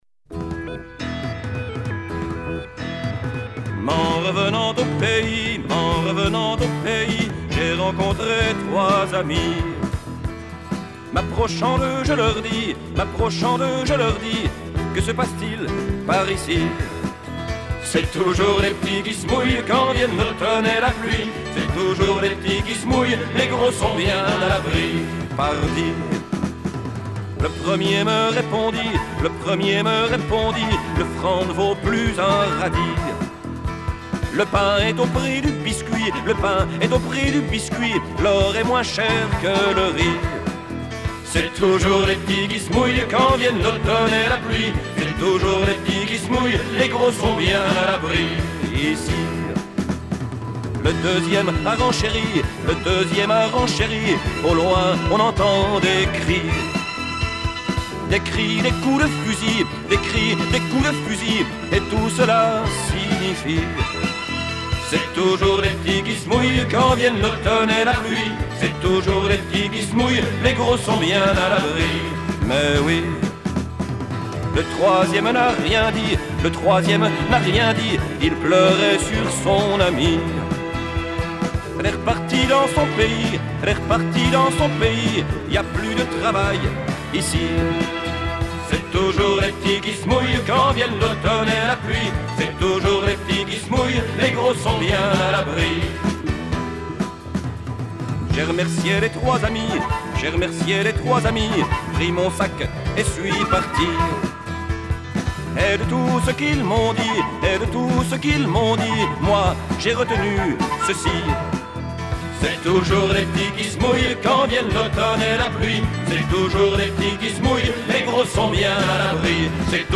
Batterie
Accordéon, bandonéon, accordina
Guitare
Piano, claviers
Guitare basse